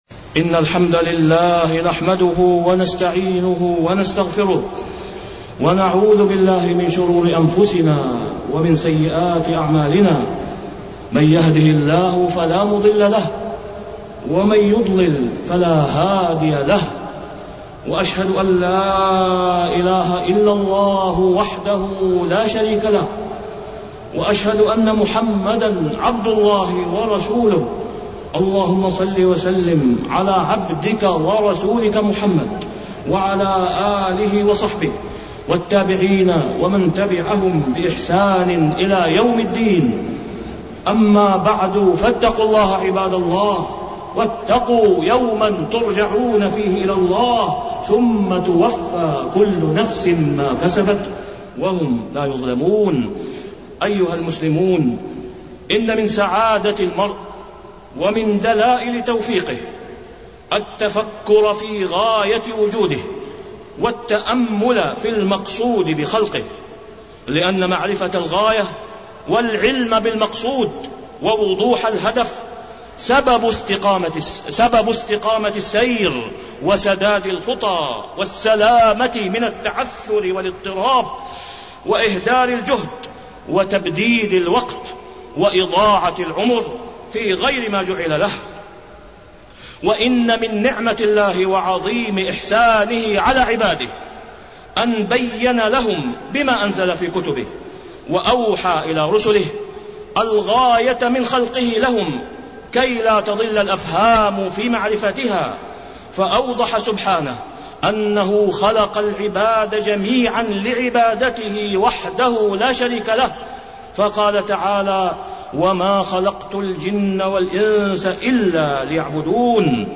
تاريخ النشر ١٧ شوال ١٤٢٩ هـ المكان: المسجد الحرام الشيخ: فضيلة الشيخ د. أسامة بن عبدالله خياط فضيلة الشيخ د. أسامة بن عبدالله خياط الاستخلاف في الأرض والأكل من الطيبات